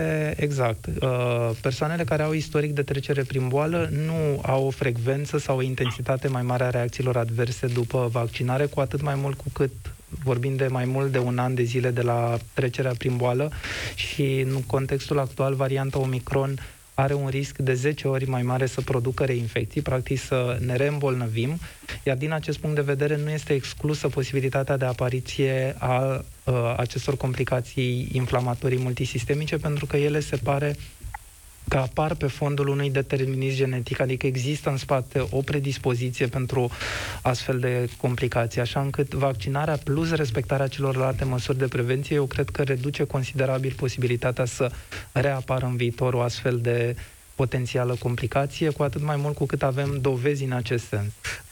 Valeriu Gheorghiță, coordonatorul campaniei de vaccinare anti-COVID în România, a fost invitatul lui Cătălin Striblea în emisiunea „România în Direct” de la Europa FM.